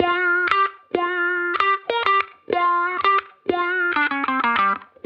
Index of /musicradar/sampled-funk-soul-samples/95bpm/Guitar
SSF_StratGuitarProc1_95G.wav